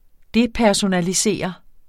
Udtale [ ˈdepæɐ̯sonaliˌseˀʌ ]